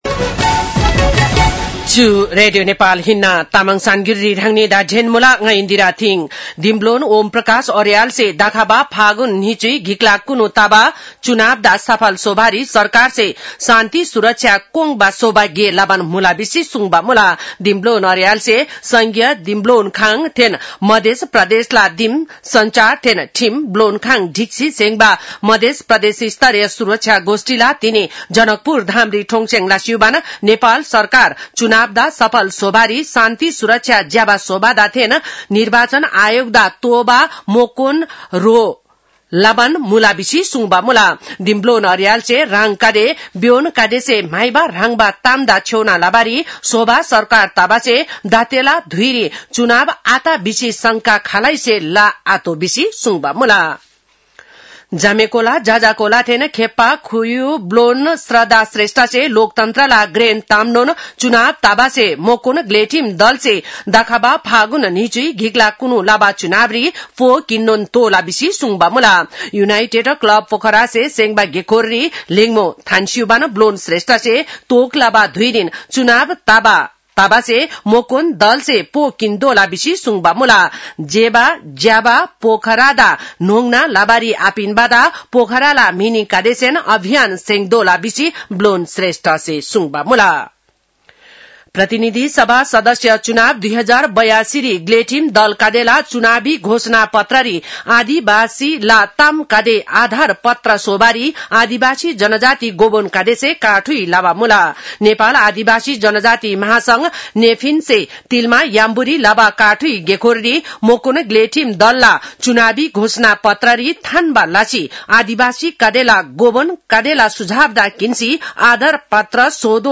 तामाङ भाषाको समाचार : ३० पुष , २०८२
Tamang-news-9-30.mp3